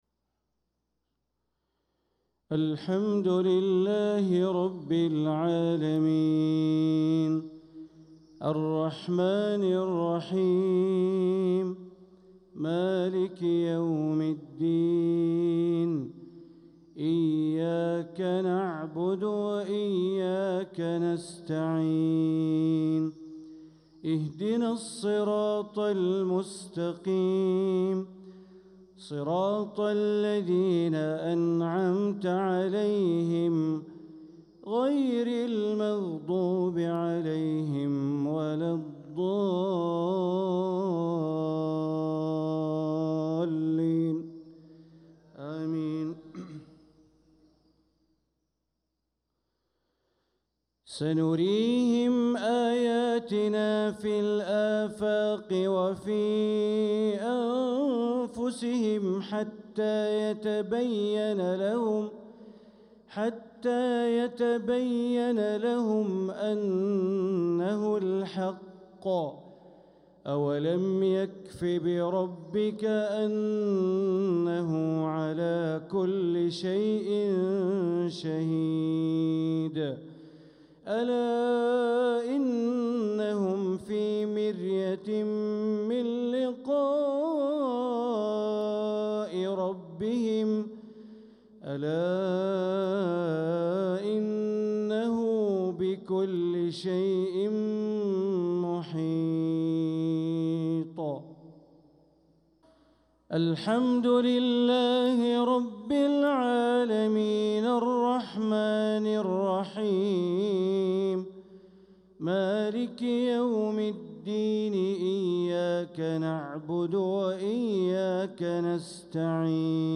صلاة المغرب للقارئ بندر بليلة 21 صفر 1446 هـ